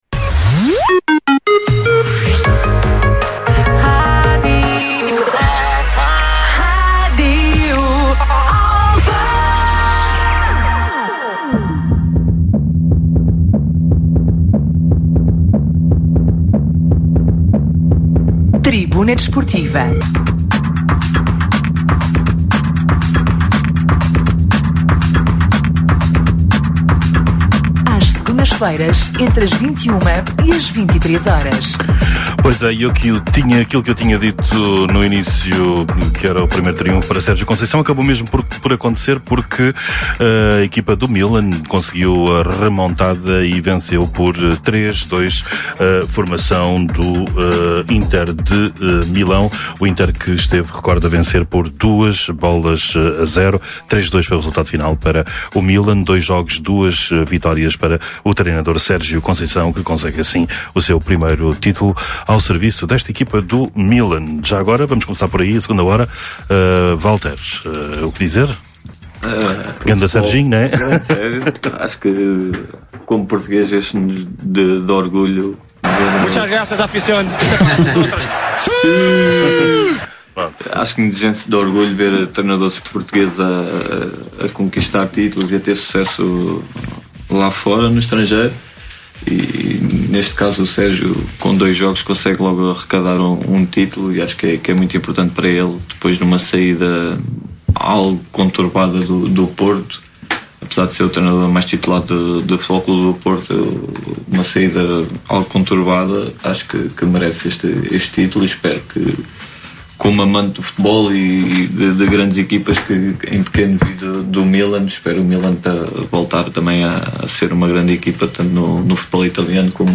Atualidade Desportiva, Entrevistas, Comentários.
Tribuna Desportiva é um programa desportivo da Rádio Alfa às Segundas-feiras, entre as 21h e as 23h.